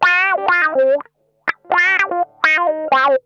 ITCH LICK 1.wav